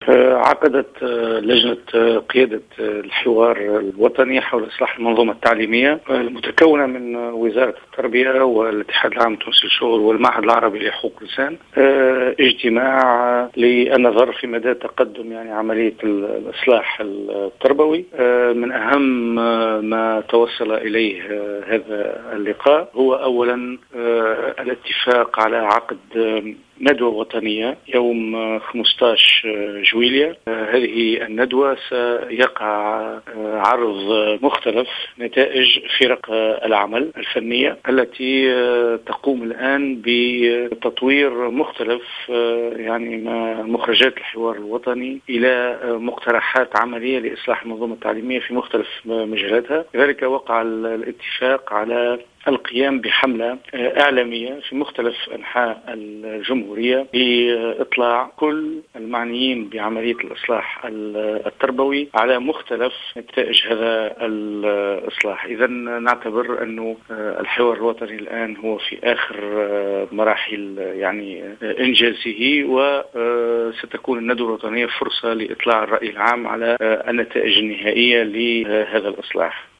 وأضاف في تصريح لـ "الجوهرة أف أم" أن هذا الاتفاق جاء بمناسبة اجتماع انعقد أمس بين الأطراف الراعية للحوار الوطني حول اصلاح المنظومة التربوية والمتمثلة في وزارة التربية والاتحاد العام التونسي للشغل والمعهد العربي لحقوق .